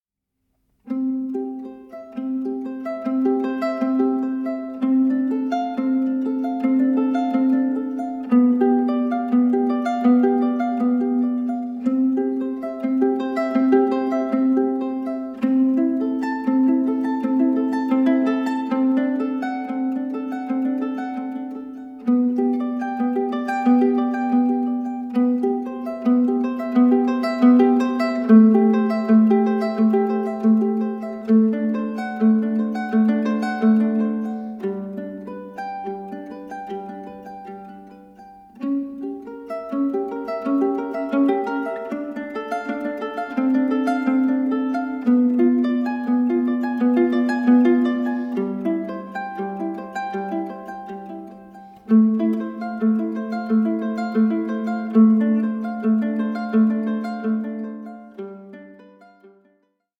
tuscan mandolin